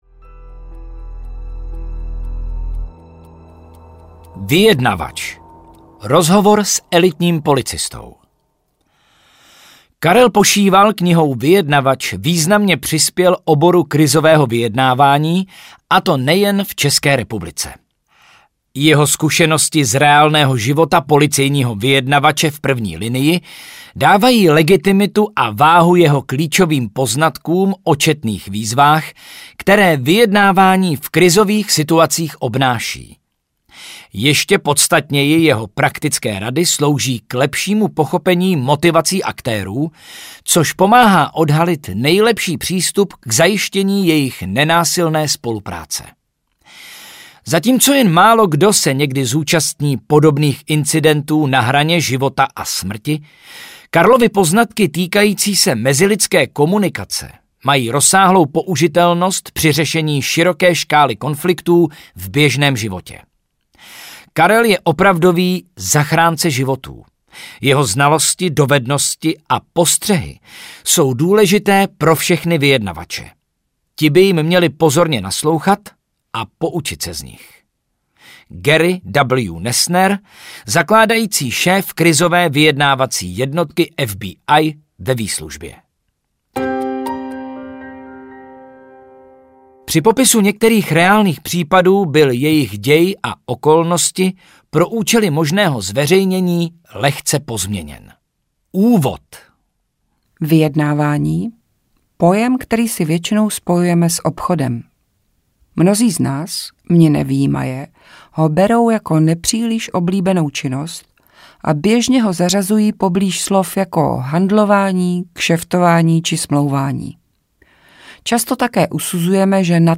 Vyjednavač audiokniha
Ukázka z knihy